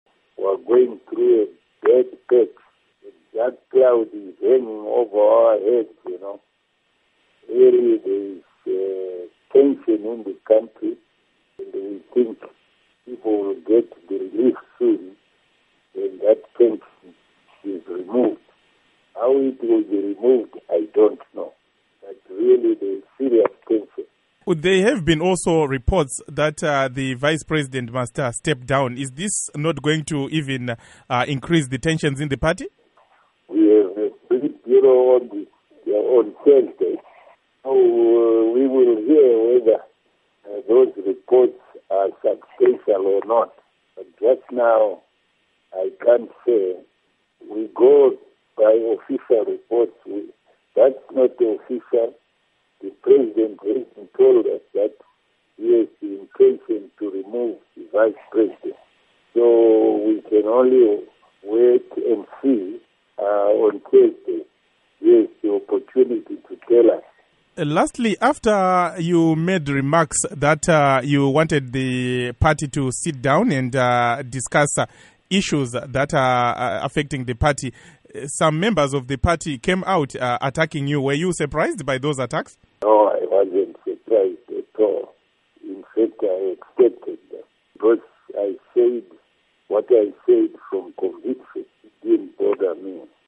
Interview With Cephas Msipa